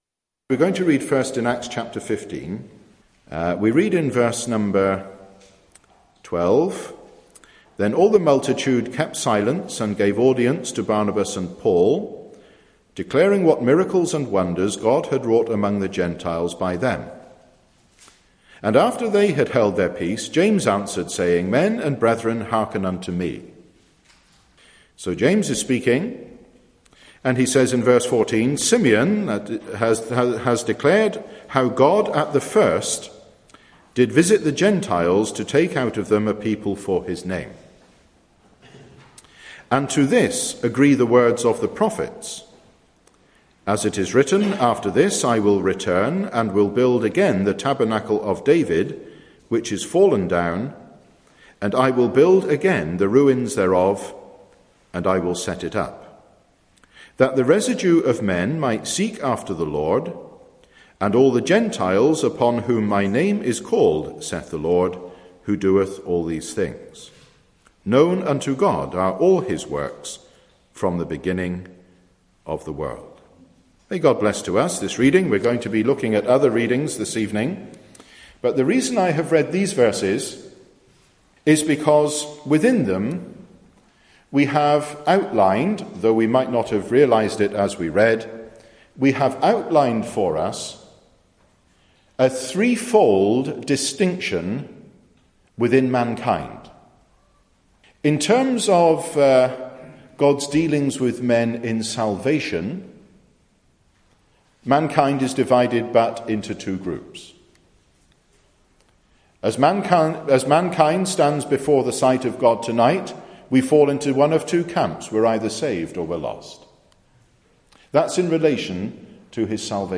(From a message delivered in David Street Gospel Hall, Liverpool, 25th Mar 2003)